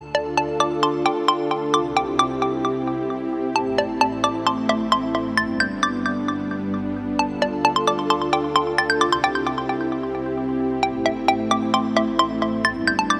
Catégorie Telephone